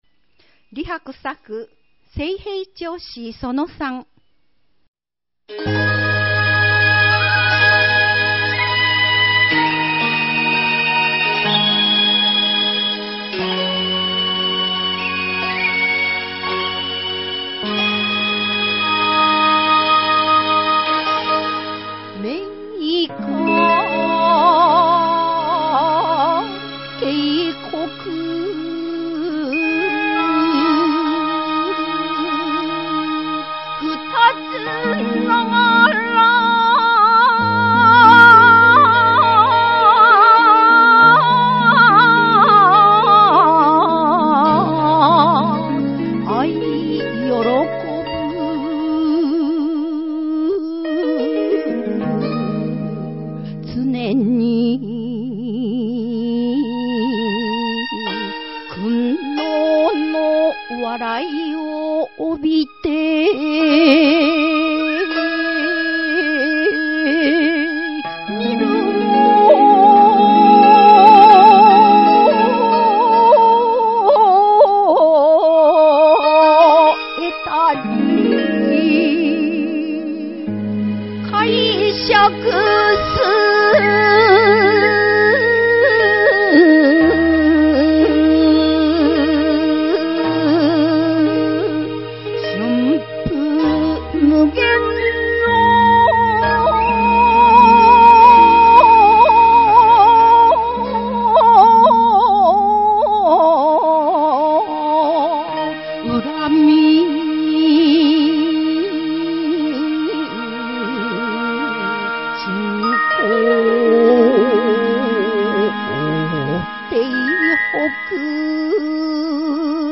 漢詩紹介